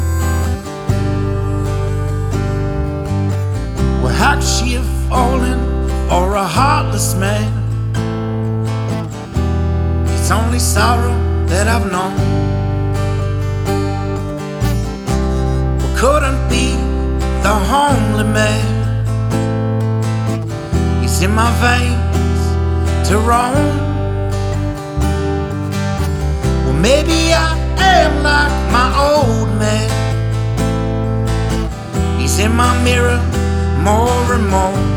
Жанр: Альтернатива / Фолк / Кантри